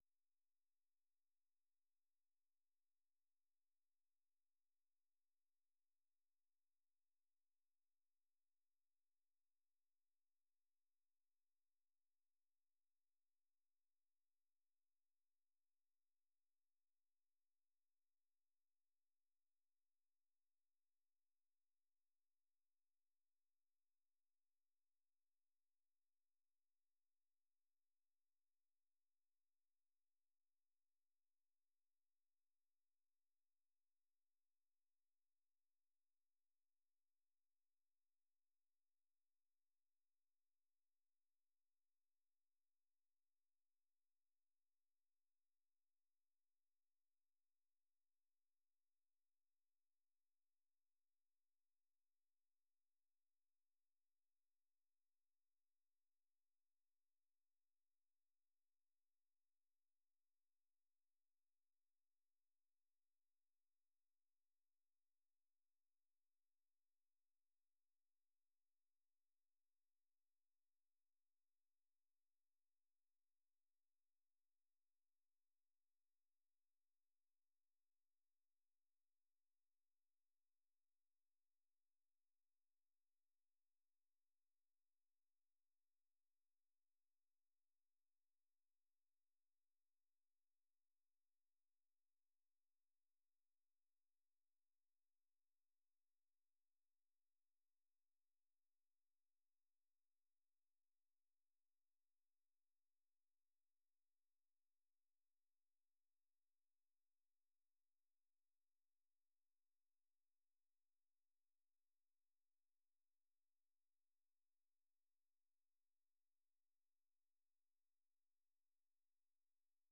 VOA 한국어 간판 뉴스 프로그램 '뉴스 투데이', 2부 방송입니다.